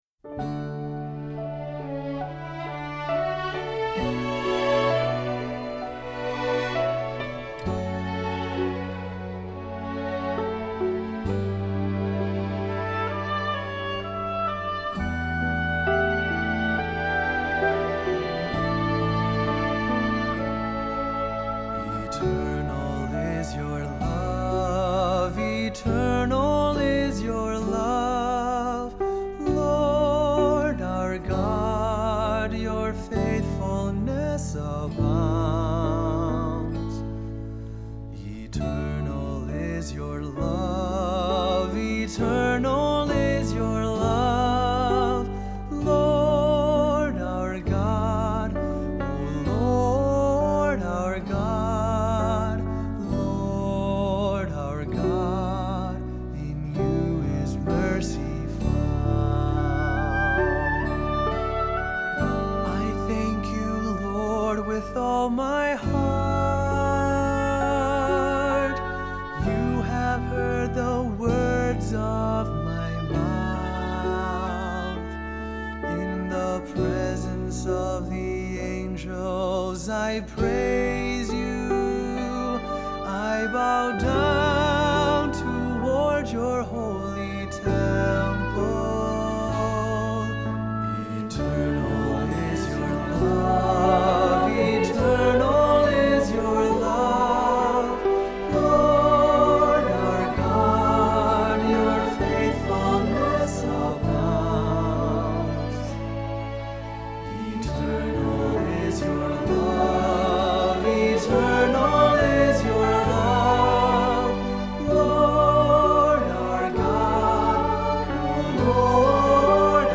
Accompaniment:      Keyboard
Music Category:      Christian
For cantor or soloist.